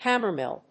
/ˈhæmɝˌmɪl(米国英語), ˈhæmɜ:ˌmɪl(英国英語)/